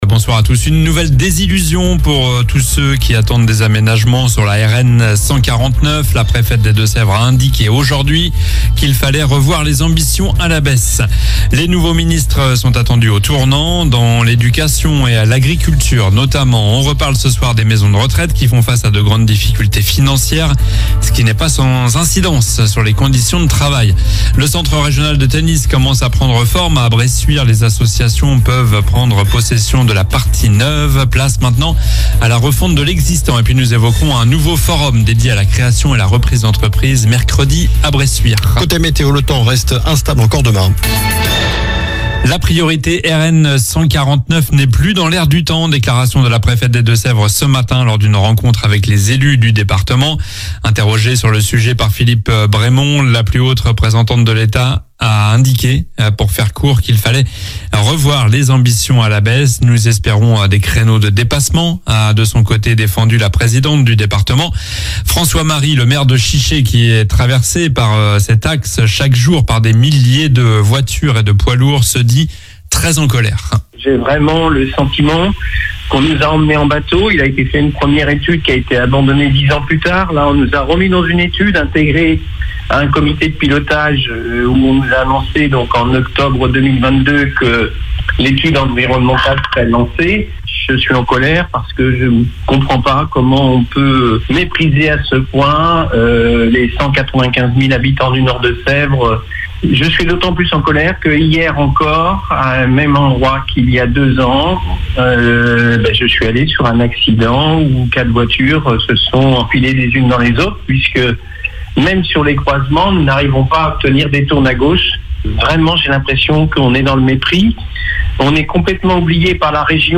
Journal du lundi 23 septembre (soir)